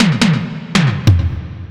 Techno / Drum / TOM005_TEKNO_140_X_SC2.wav